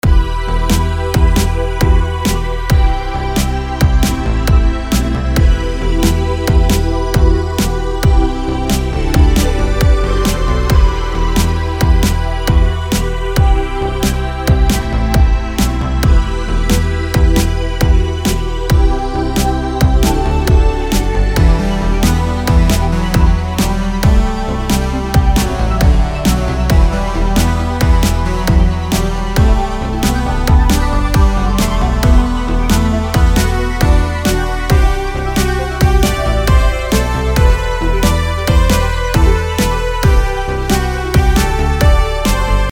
I was arranging the track with my DAW set to 3 beats per bar at 135bpm.
A snippet of the actual arranged song